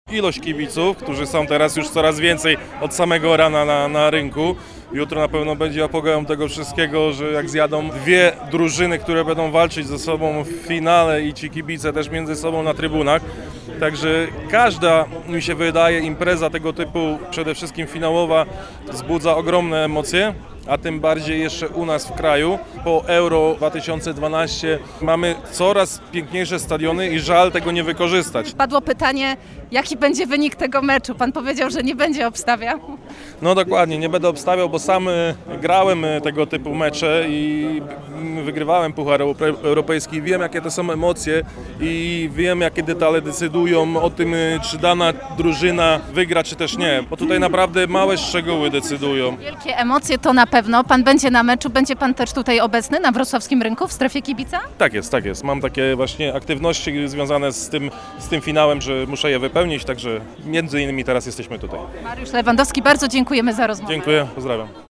W inauguracji wzięli udział również polscy piłkarze, wśród nich Michał Żewłakow i dolnoślązak, Mariusz Lewandowski. Zaprezentowali Puchar Ligii Konferencji i pozytywnie ocenili organizację.